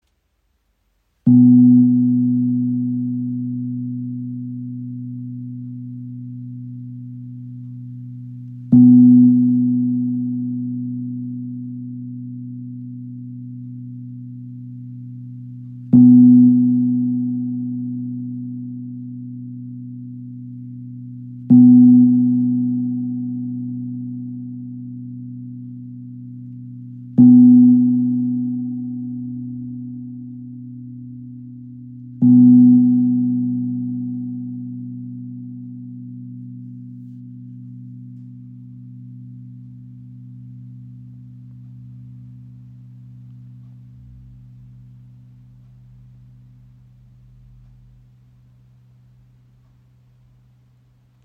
Buckelgong aus Burma – Herzklang der alten Welt | ø 50 cm | B2 in 440 Hz
• Icon Tiefer, klarer Klang mit zentrierender Wirkung – ideal für Meditation
Sein Klang wirkt sammelnd, beruhigend und lädt dazu ein, innerlich still zu werden.
Handgehämmert aus Bronze, entfaltet er beim Anschlag seines Mittelpunkts einen tiefen, klaren Ton – sanft, erdend, durchdringend.